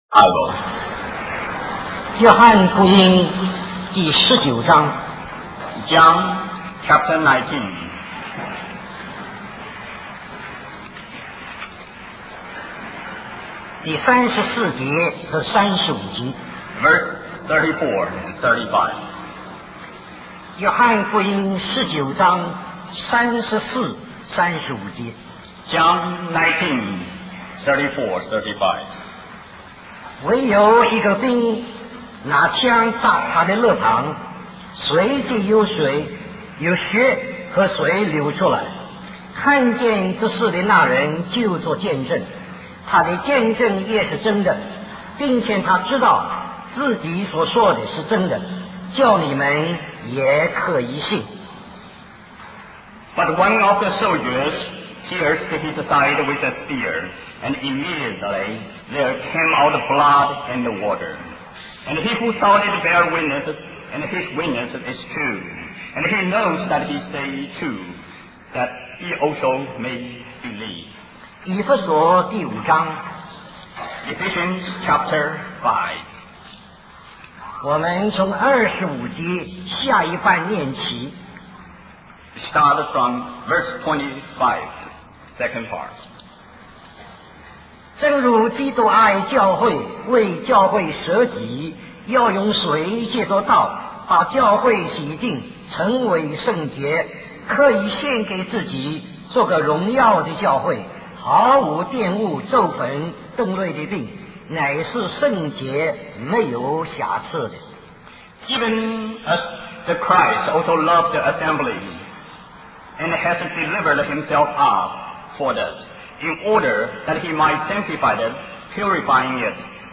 A collection of Christ focused messages published by the Christian Testimony Ministry in Richmond, VA.
Special Conference For Service, Hong Kong